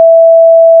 Building (5): chest, door_open, door_close, hammer, repair
**⚠  NOTE:** Music/SFX are PLACEHOLDERS (simple tones)
chest_open.wav